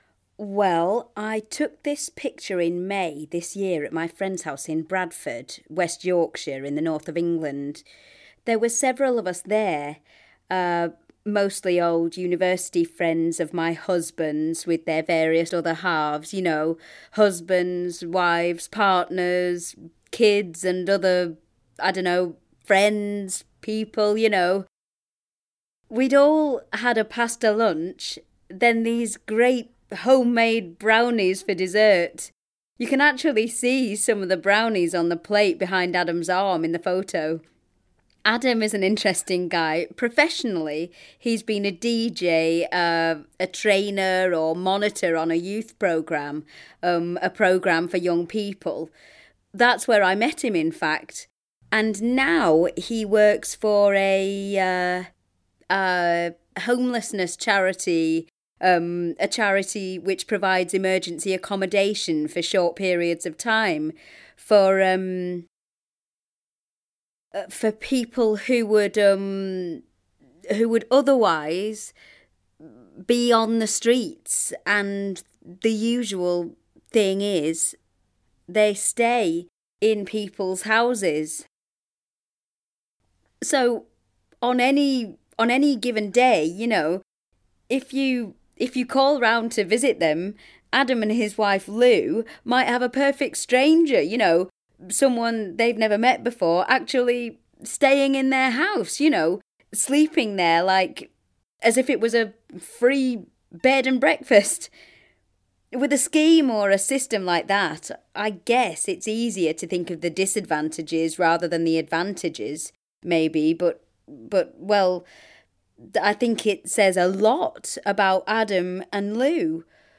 Each pack in this series contains an audio recording with a photographer talking about an image they have taken and a complete downloadable lesson plan on how to exploit the image and the audio.